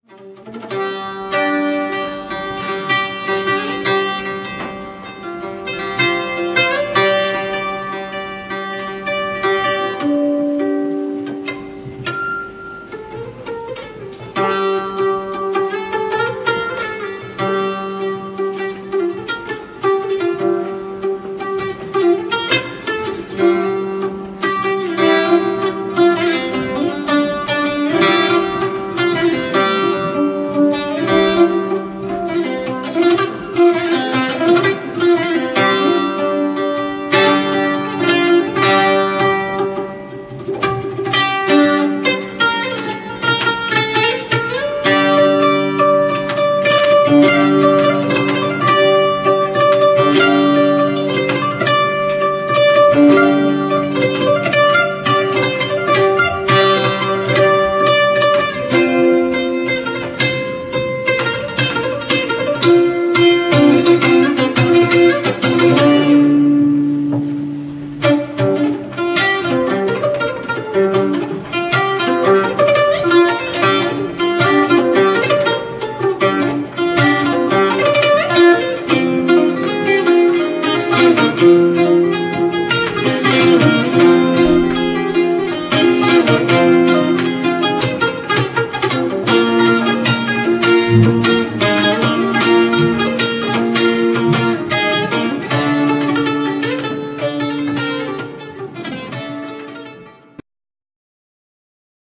Фрагмент концерта ( 2,8 MB, Real Media)